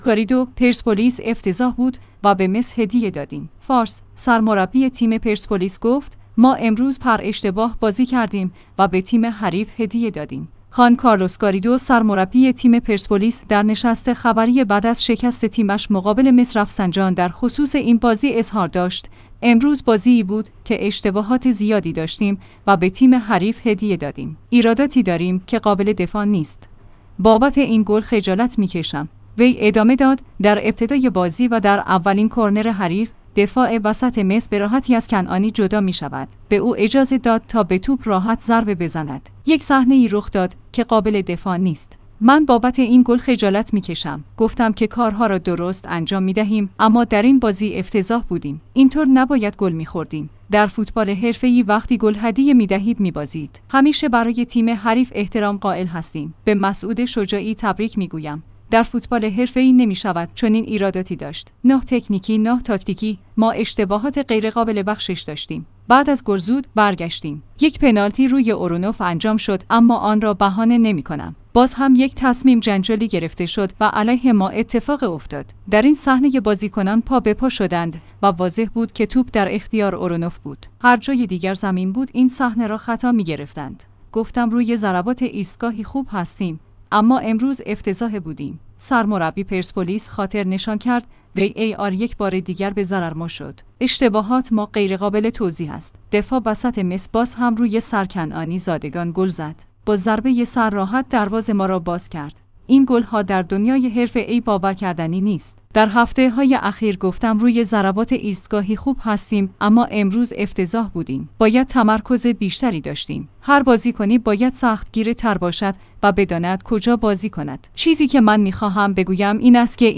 خوان کارلوس گاریدو سرمربی تیم پرسپولیس در نشست خبری بعد از شکست تیمش مقابل مس رفسنجان در خصوص این بازی اظهار داشت: امروز بازی ای بود که اشتباهات زیادی داشتیم و به تیم حریف هدیه دادیم.